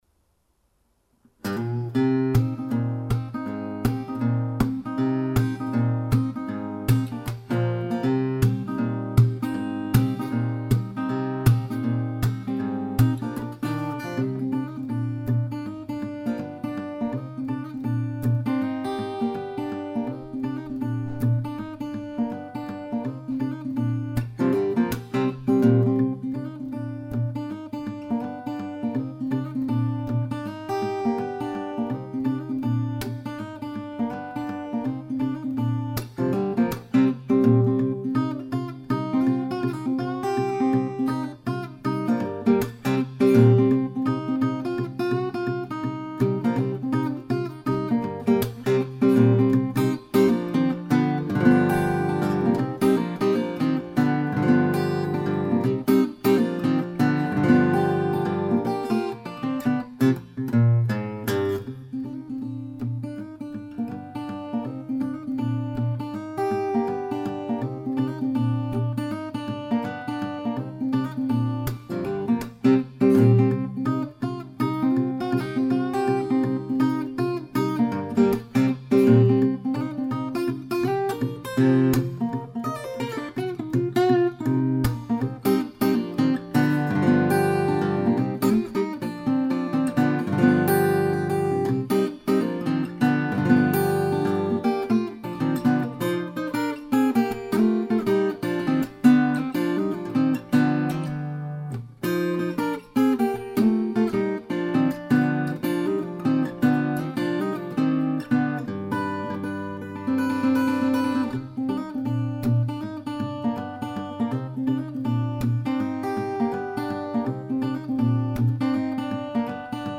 いろんな人のアレンジがありますが，ついに私もアレンジしてみました。